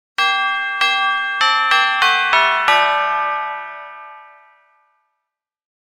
Festive-ding-dong-merrily-on-high-doorbell-sound-effect.mp3